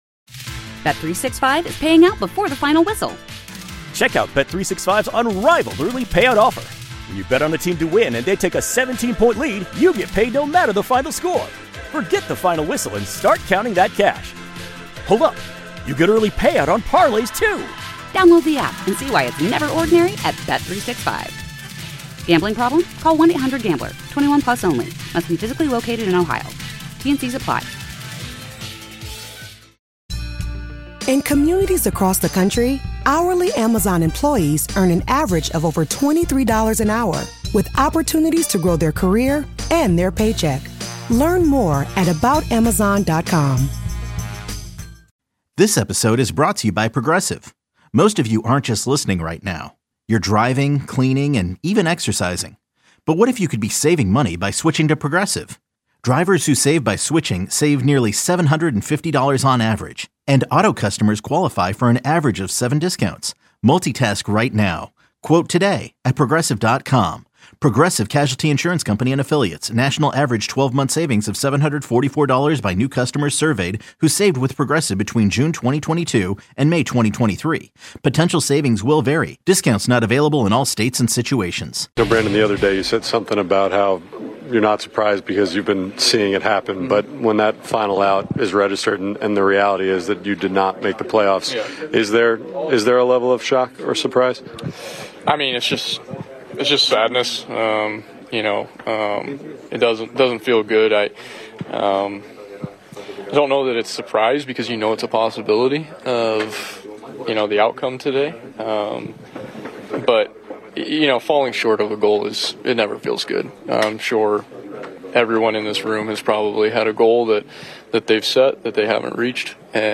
Interviews and coverage of Mets baseball in New York.